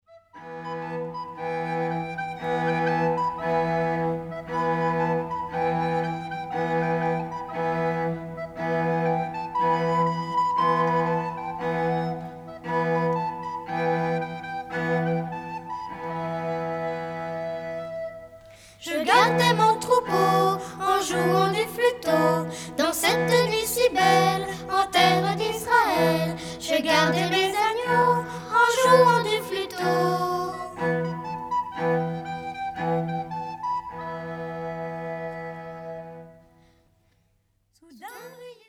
Chants de Noël pour enfants
Format :MP3 256Kbps Stéréo